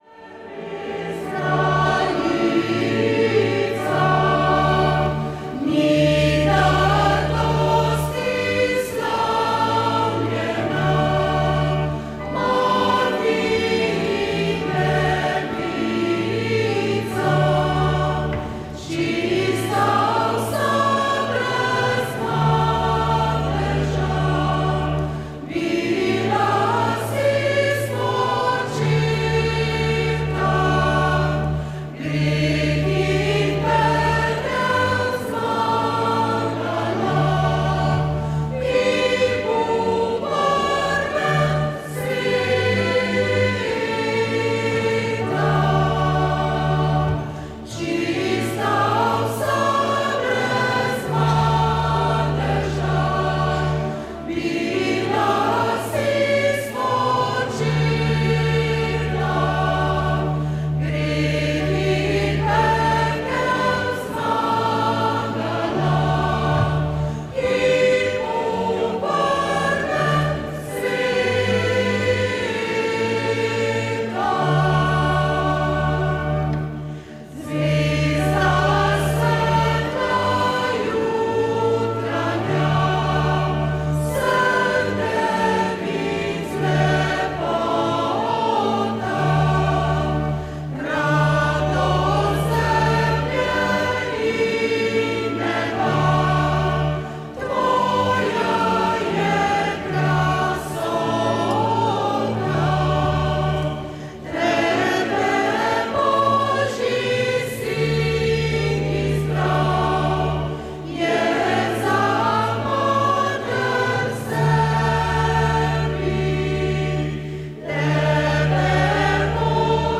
Mašniško posvečenje iz ljubljanske stolnice dne 29. 6.
29. junija se spominjamo sv. Petra, ki ga je Kristus določil za skalo, na kateri bo zidal svojo Cerkev in sv. Pavla, ki je ponesel njegovo ime med pogane. Tako ste v našem programu ob 16. uri lahko prisluhnili prenosu mašniškega posvečenja iz ljubljanske stolnice.